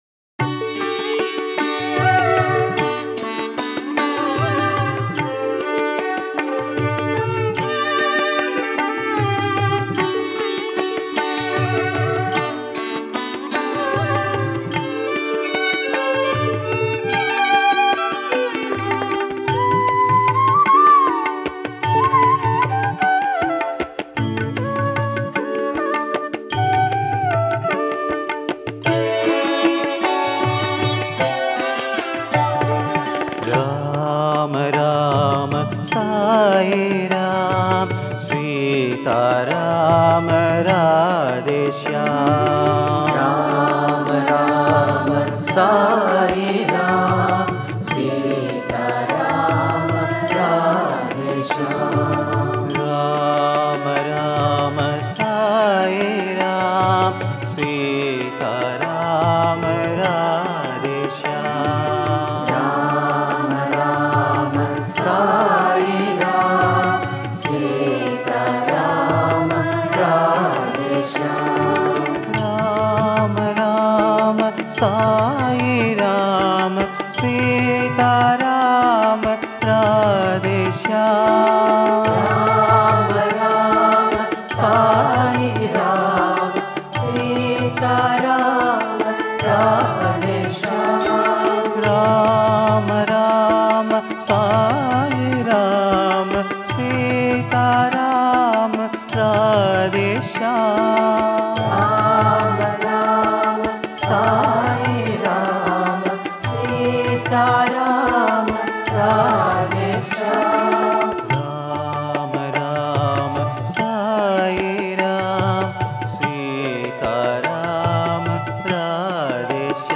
Home | Bhajan | Bhajans on various Deities | Rama Bhajans | 46-RAMA-RAMA-SAIRAM-SEETHARAM